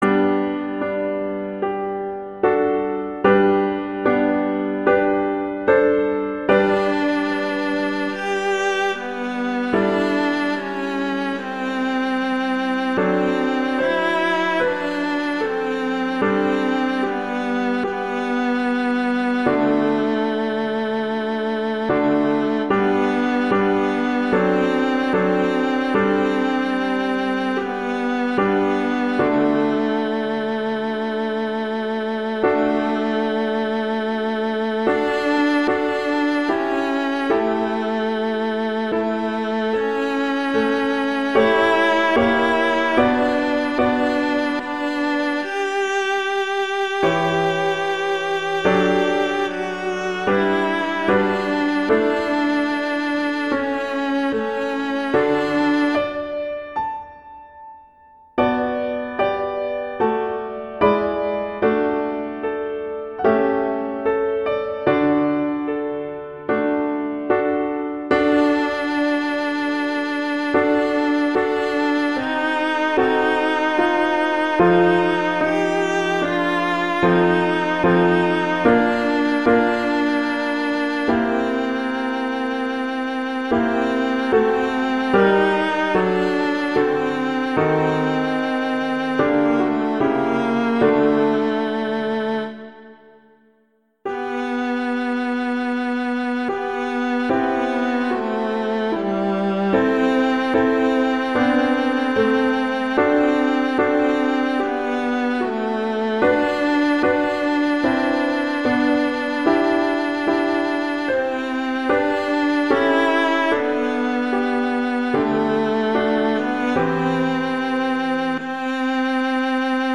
arrangements for viola and piano